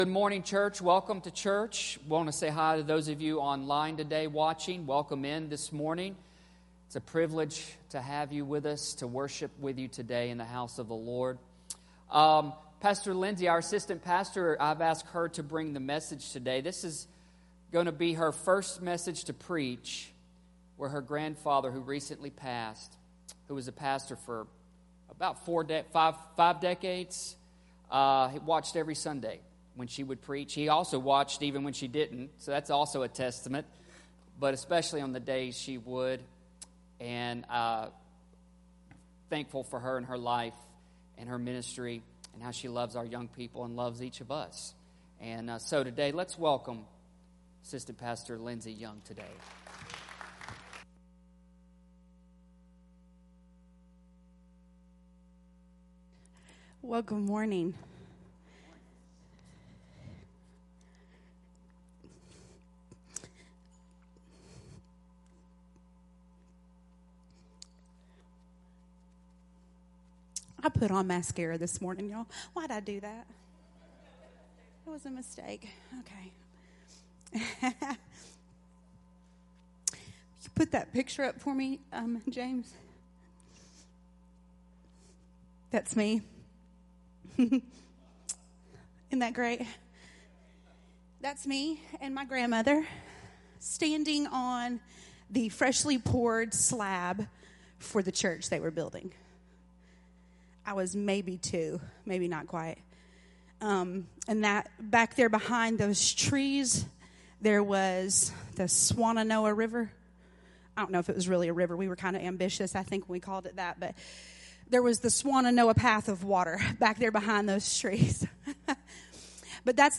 New Life Church Sermons